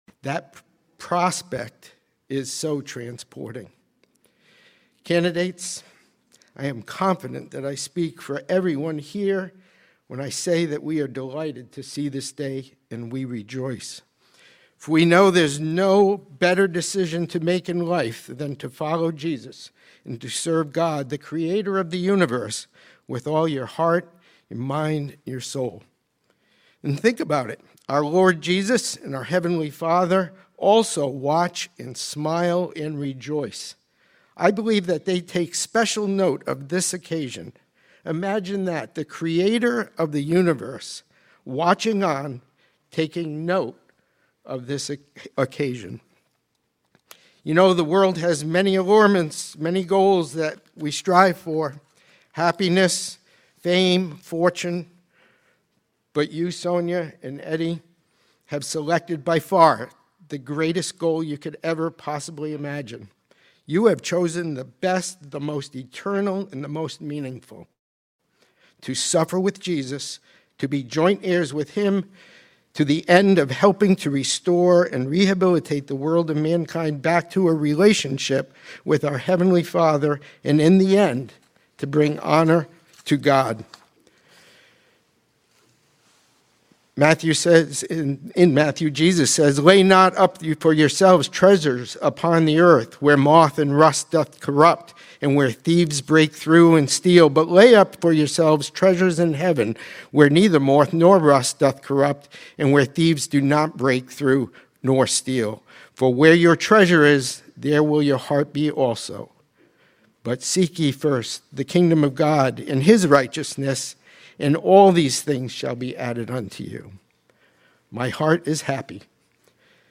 Series: 2026 Wilmington Convention